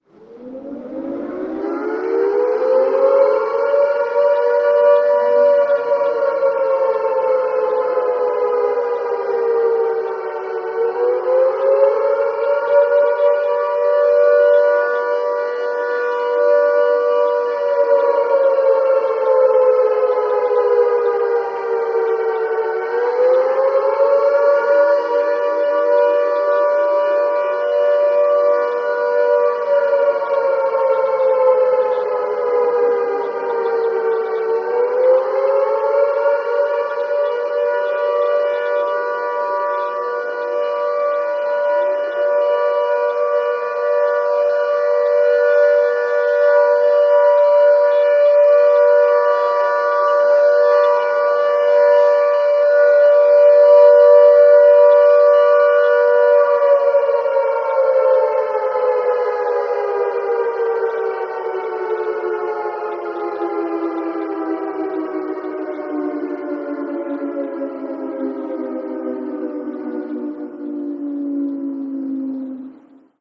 alarmAirraidSiren.ogg